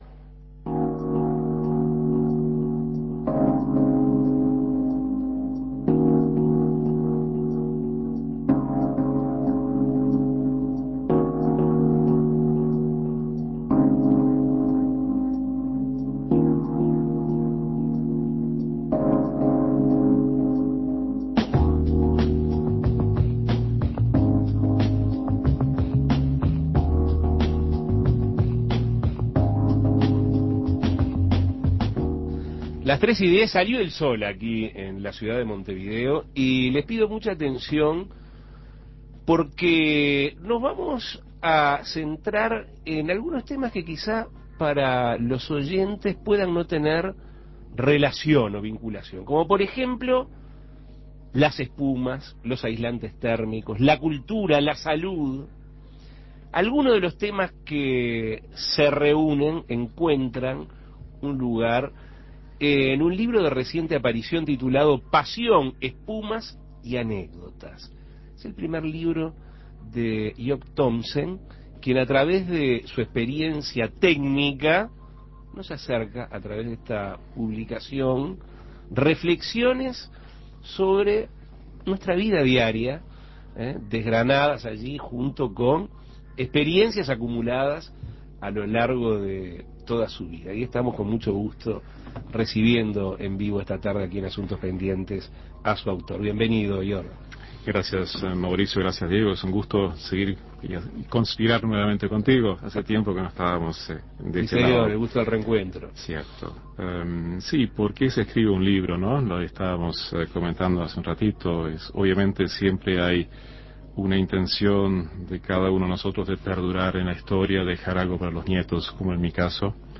Entrevistas "Pasión